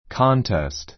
kɑ́ntest カ ンテ スト ｜ kɔ́ntest コ ンテ スト